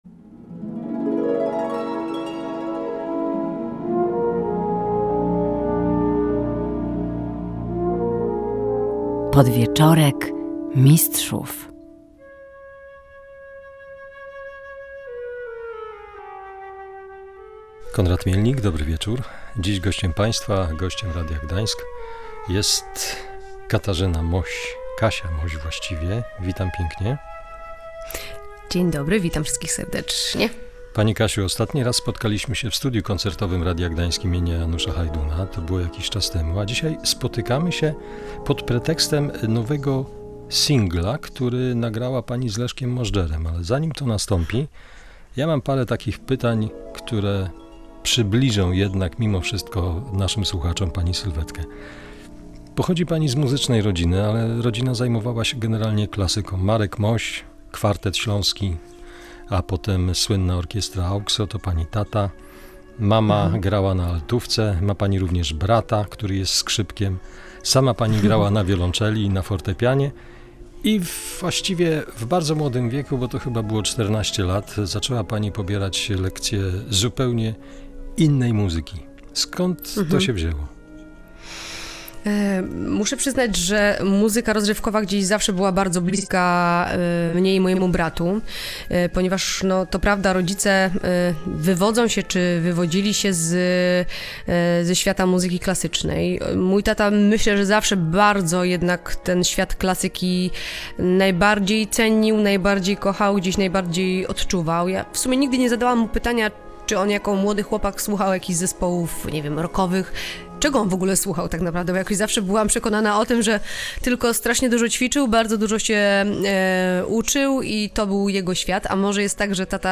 Ostatnie spotkanie z Katarzyną Moś odbyło się w Studiu Koncertowym Radia Gdańsk im. Janusza Hajduna. Tym razem pretekstem do rozmowy był nowy singiel.